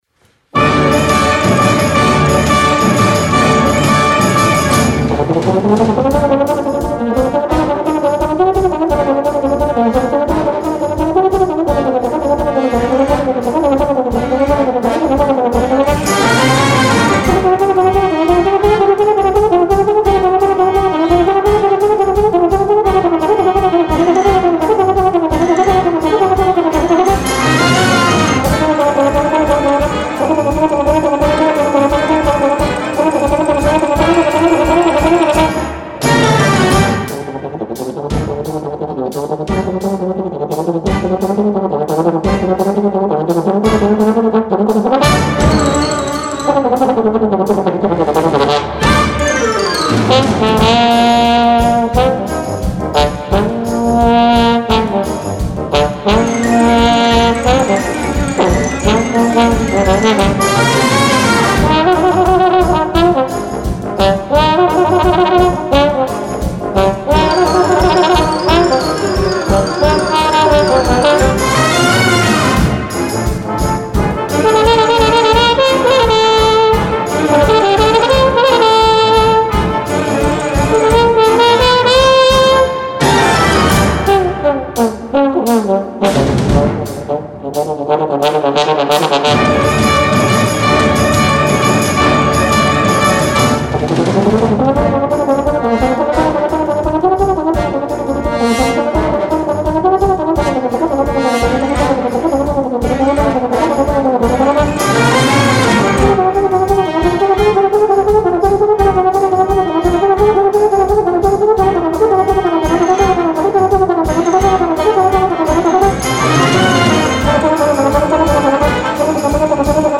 Gattung: Solo Trompete und Blasorchester
Besetzung: Blasorchester